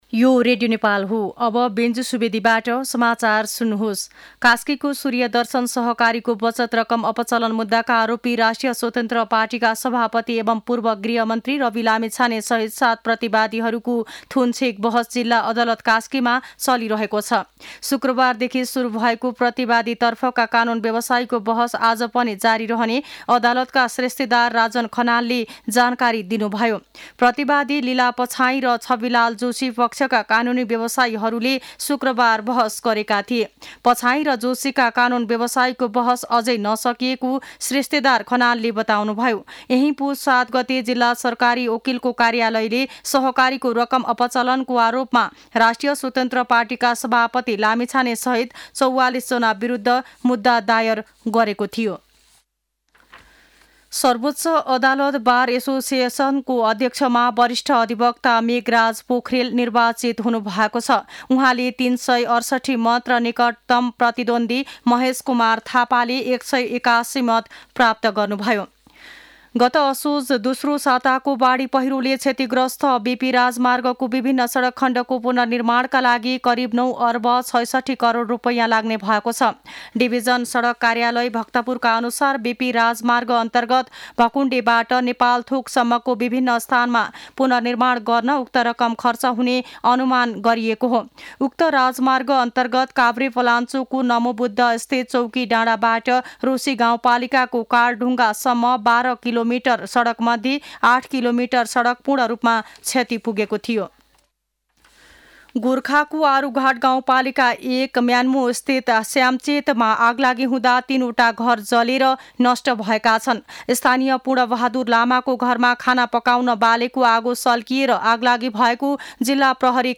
दिउँसो १ बजेको नेपाली समाचार : २२ पुष , २०८१
1-pm-News-09-21.mp3